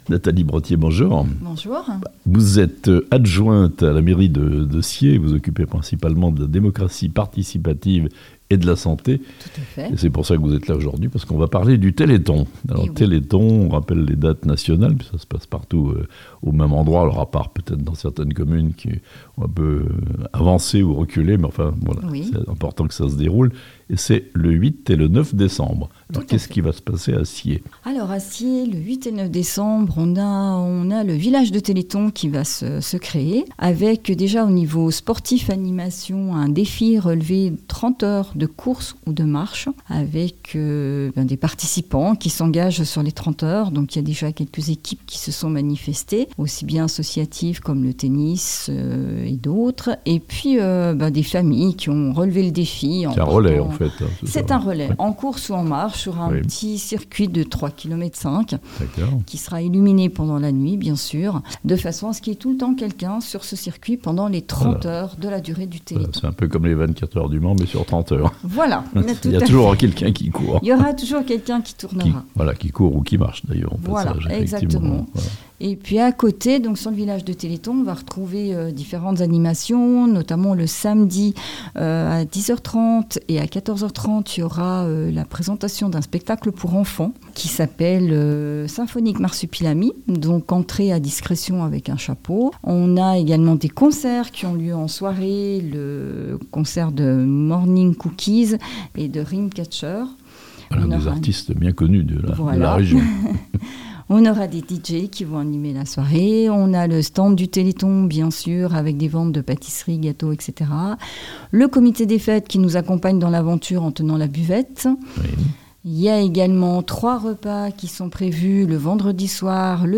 Les 8 et 9 décembre, Sciez veut devenir le "Village du Téléthon" (interview)
Présentation de cette édition 2023 exceptionnelle du Téléthon à Sciez par Nathalie Brothier, maire-adjointe chargée de la démocratie participative et de la santé.